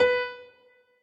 admin-amethyst-moon/b_piano2_v100l32-5o5b.ogg